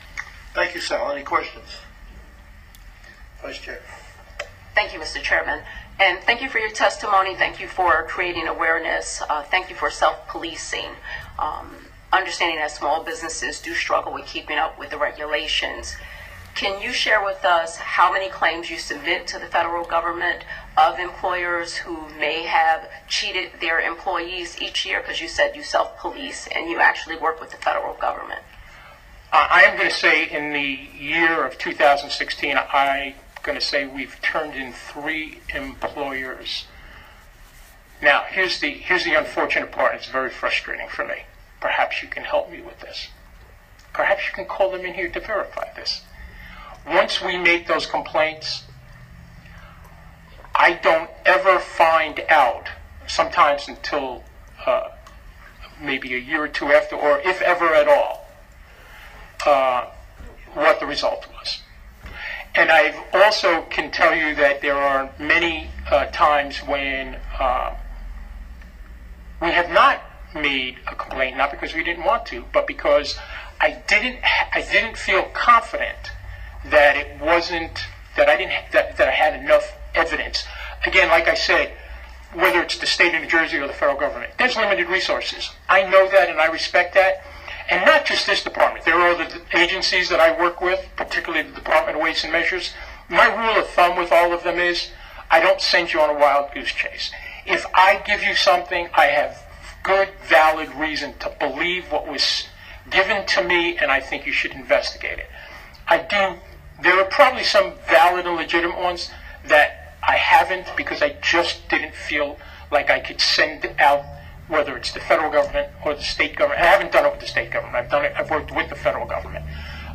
CLICK HERE to listen to the question and answer discussion that occurred between myself and various Legislators after my testimony.